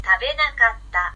ta be na ka t ta